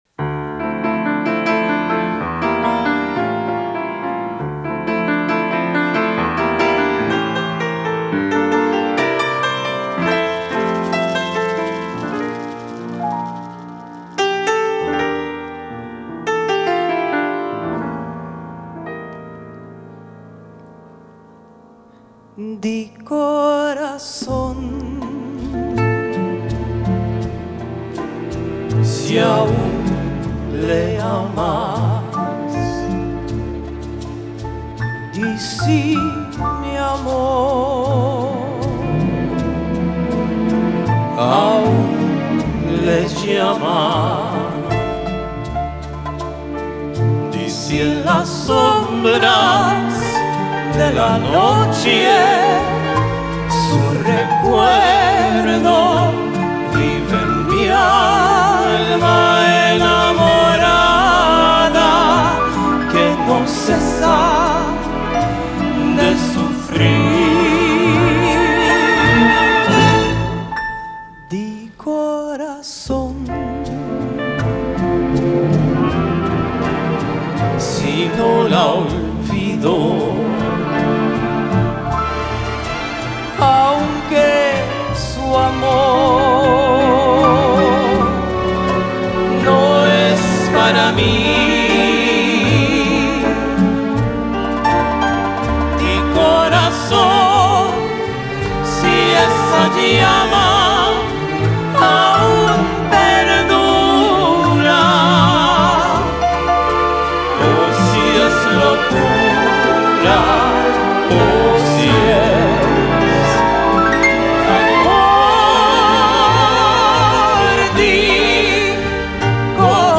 Orchestra
Pops Concert